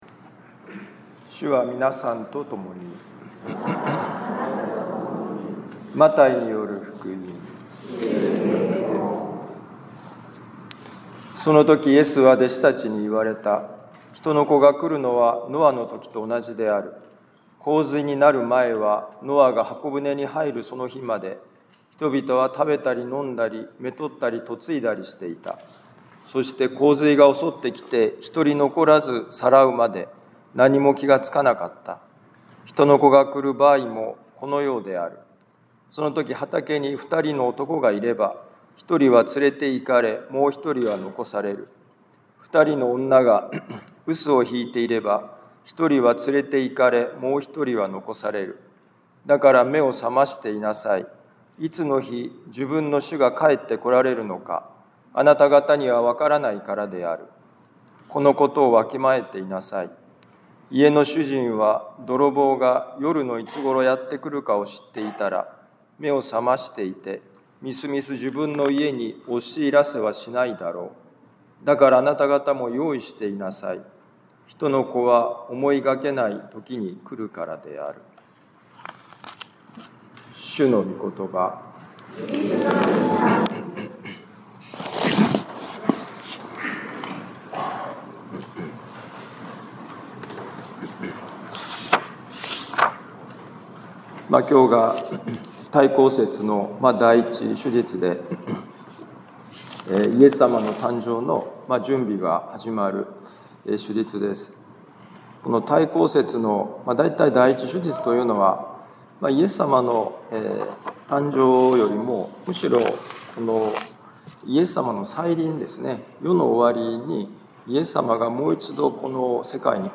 【ミサ説教】
マタイ福音書24章37-44節「最悪を想定し、最善を尽くせ」2025年11月30日待降節第１主日ミサ カトリック防符教会